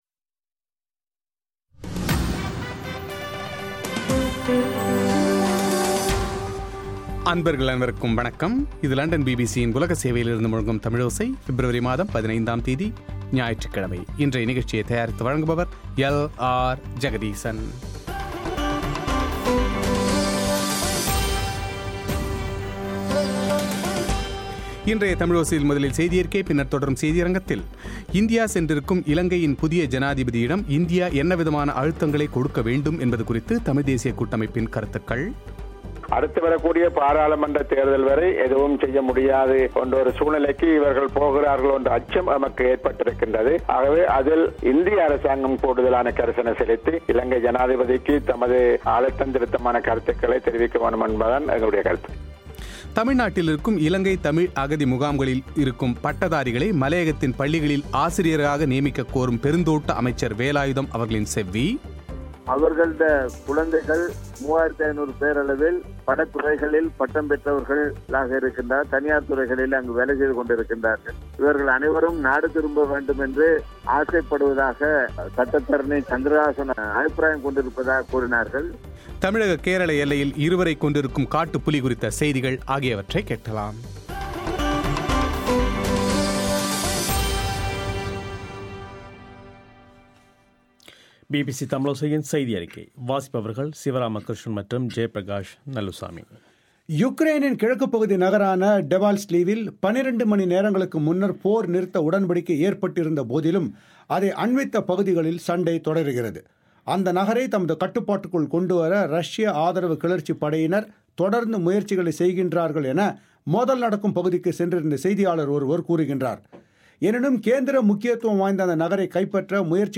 தமிழ்நாட்டின் இலங்கை தமிழ் அகதிமுகாம்களில் உள்ள பட்டதாரிகளை மலையக பள்ளிகளின் ஆசிரியராக நியமிக்கக்கோரும் பெருந்தோட்ட அமைச்சர் வேலாயுதத்தின் செவ்வி;